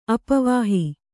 ♪ apavāhi